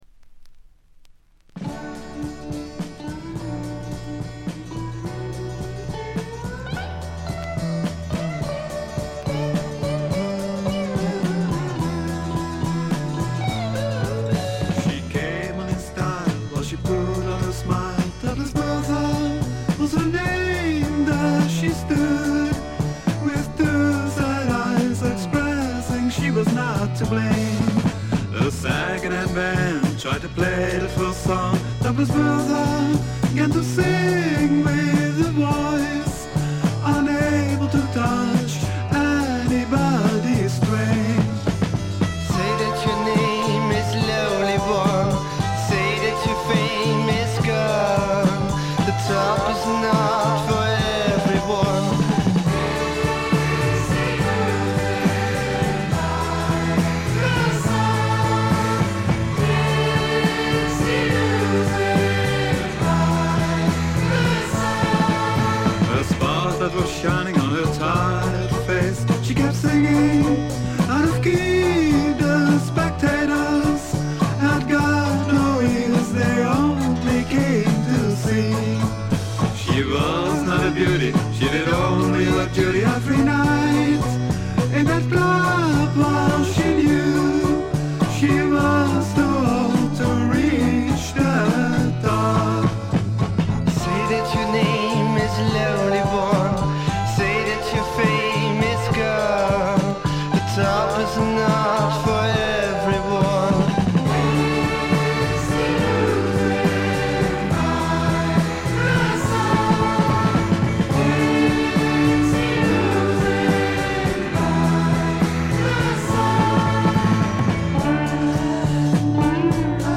ざっと全編試聴しました。バックグラウンドノイズ、チリプチやや多め大きめ。
全体はチェンバー・ロック風な雰囲気ですが、フォークロックとか哀愁の英国ポップ風味が濃厚ですね。
試聴曲は現品からの取り込み音源です。